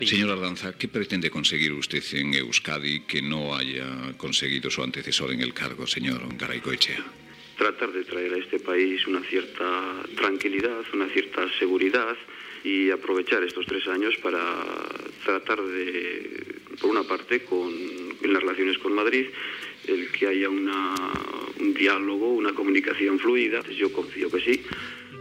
Fragment d'una entrevista al lehendakari José Antonio Ardanza
Info-entreteniment